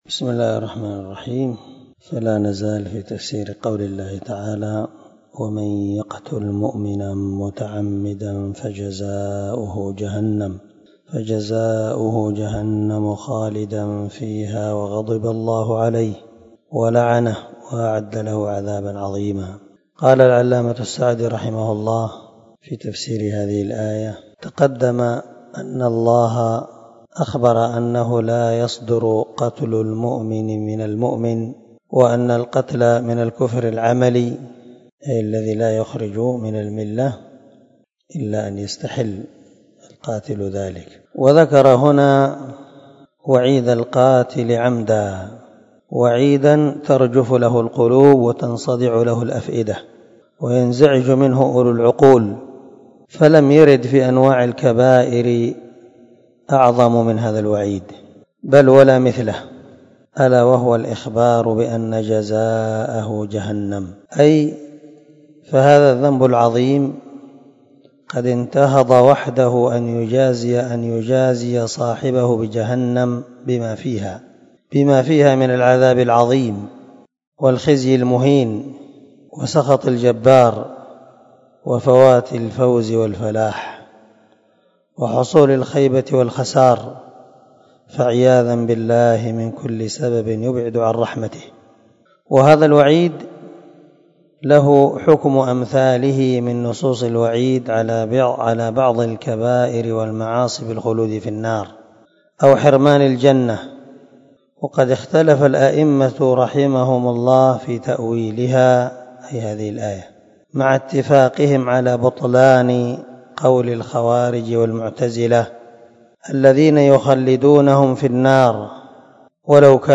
294الدرس 62 تابع تفسير آية ( 93 ) من سورة النساء من تفسير القران الكريم مع قراءة لتفسير السعدي